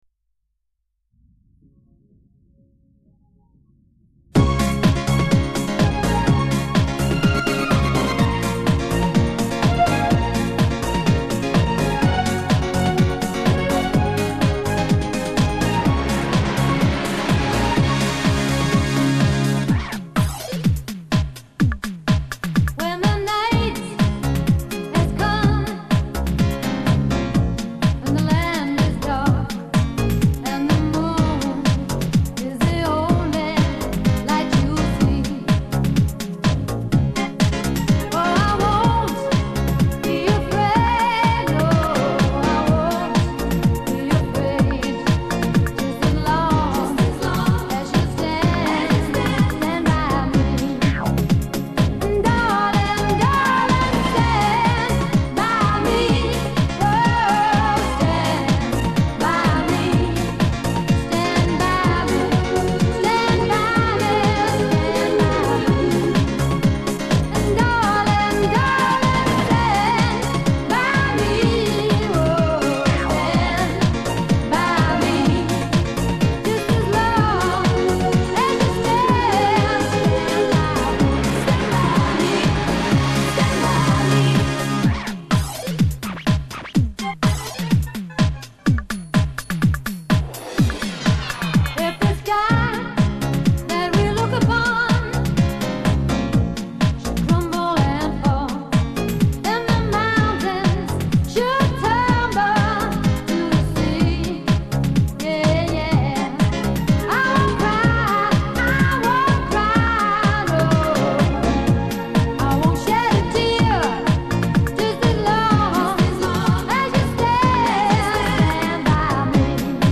卡带转录WAV.320K.MP3
迪斯科
上世纪90年代流行的外国音乐舞曲